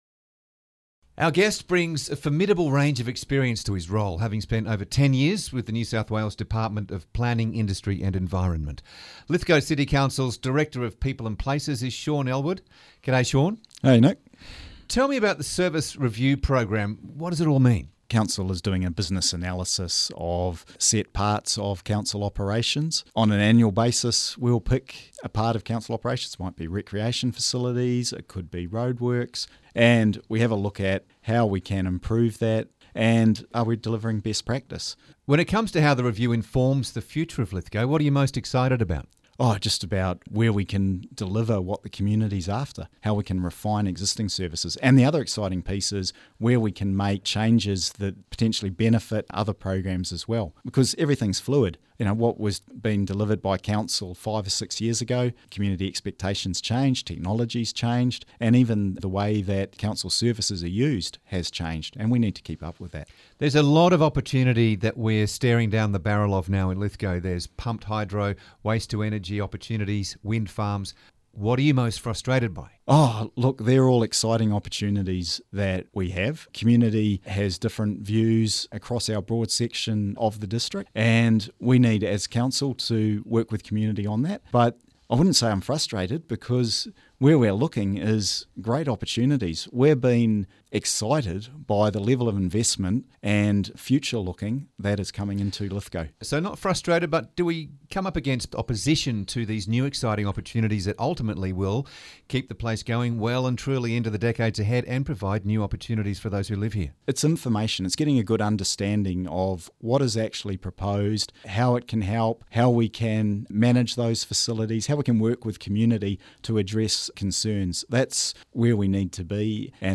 interviews
Audio courtesy of 2LT and Move FM